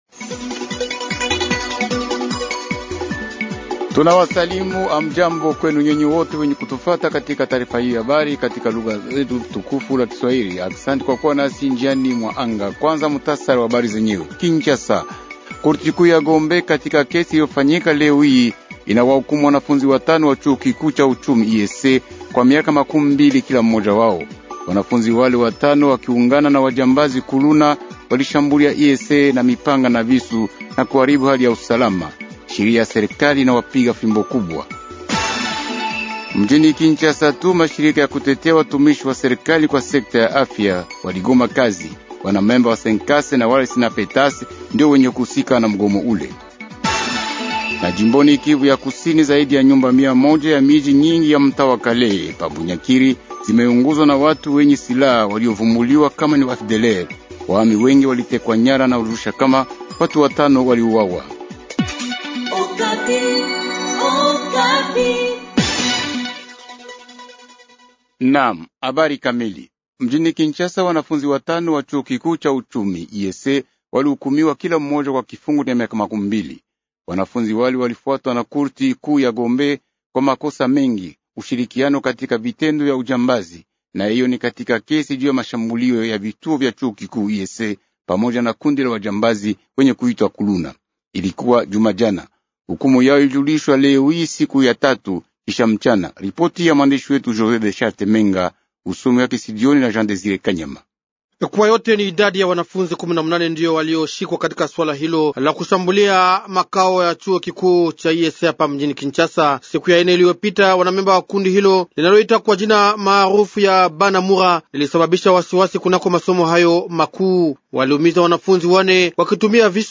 Journal Swahili Soir | Radio Okapi